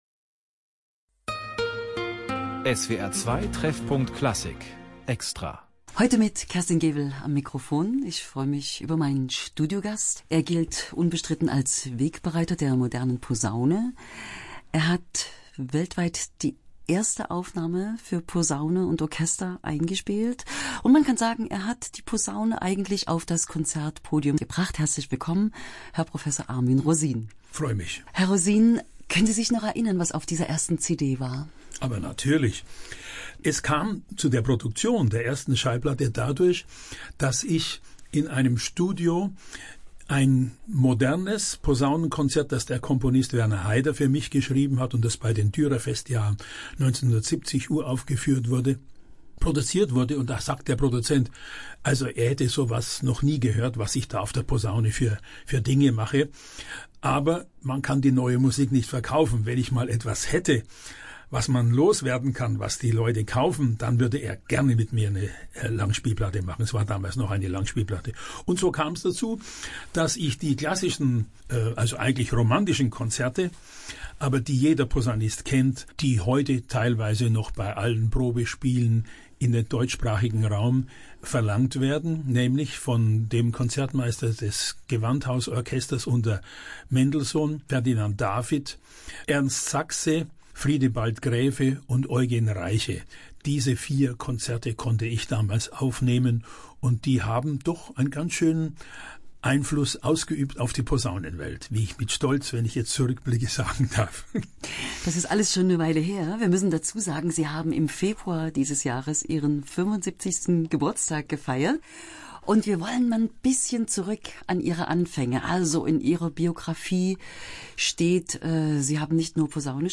Interview vom 27.